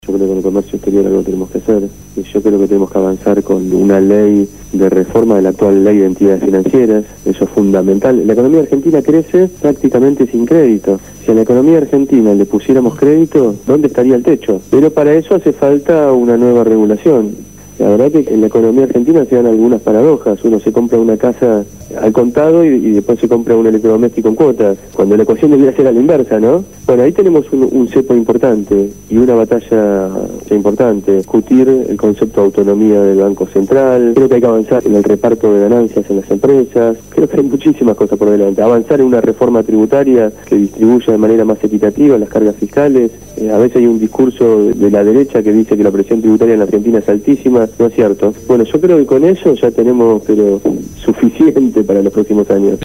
Ariel Pasini se refirió en esta entrevista a la Asignación Universal por Hijo, las jubilaciones, el Banco del Sur, la Ley de Tierras y la economía argentina.